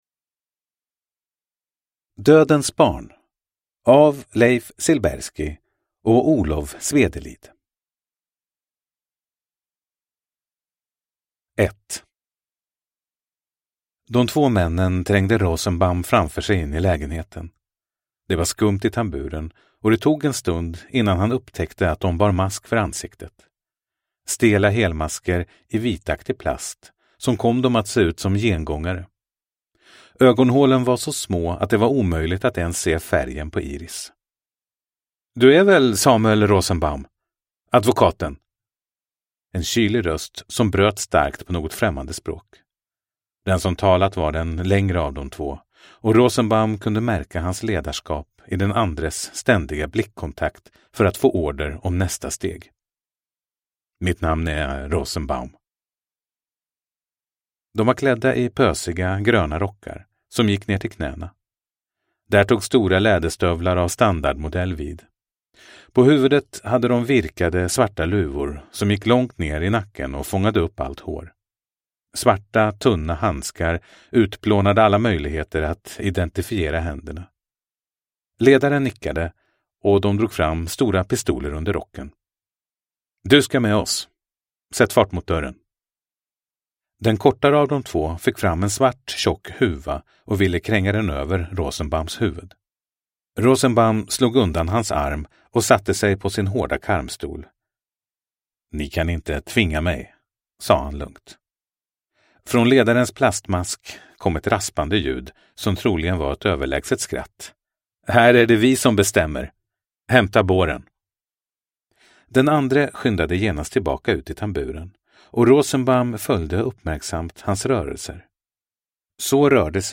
Dödens barn – Ljudbok